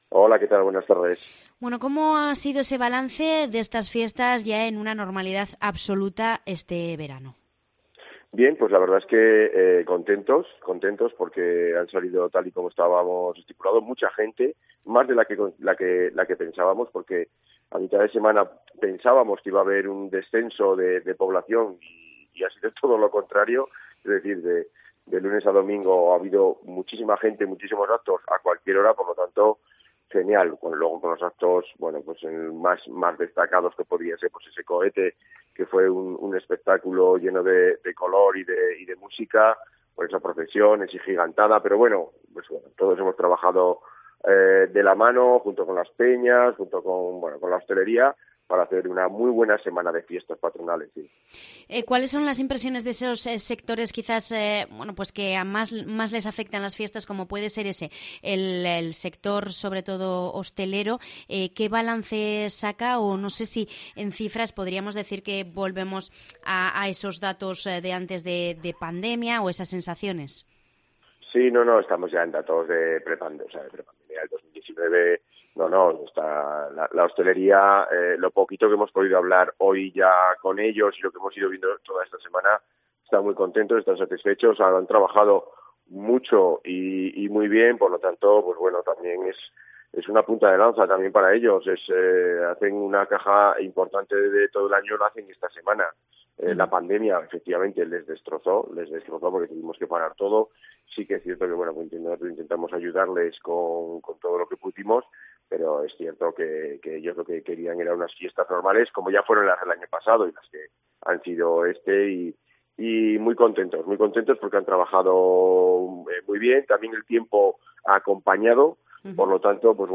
Alejandro Toquero, alcalde de Pamplona hace balance de las fiestas en Cope Navarra